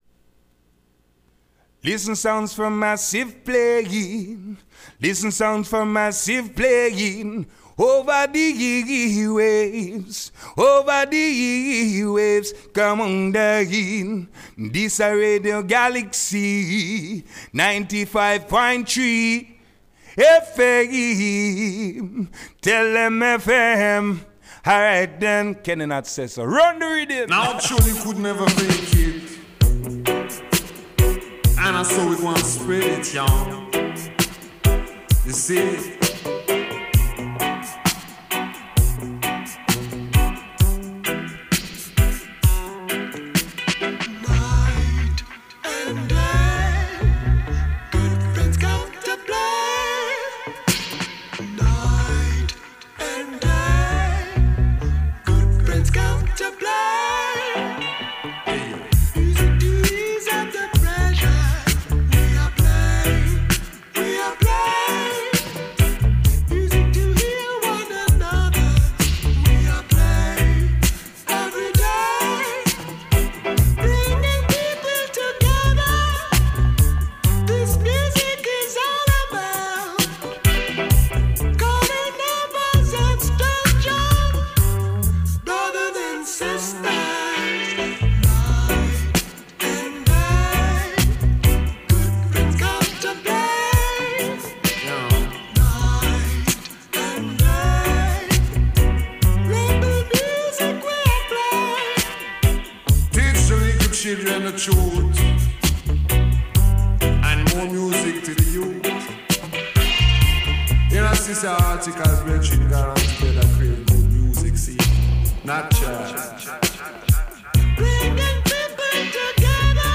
rendez-vous reggaephonique
dans les studios